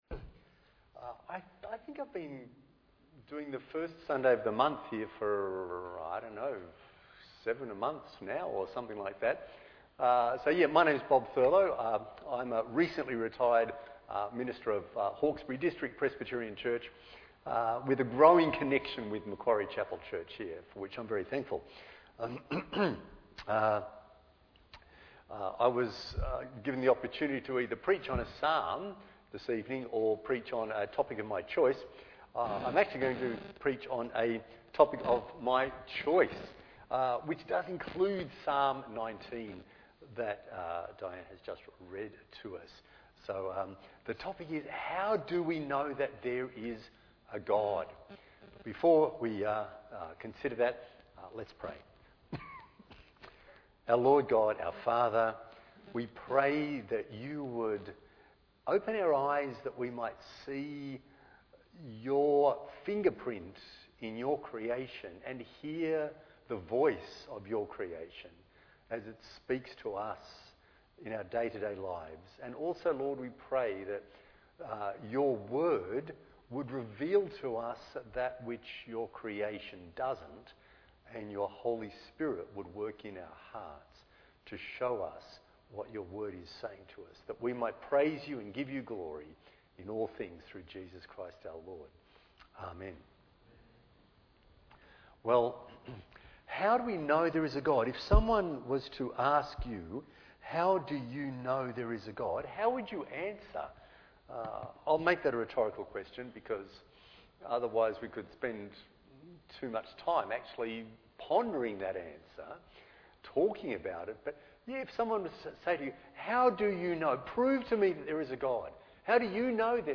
Bible Text: Psalm 19:1-14 | Preacher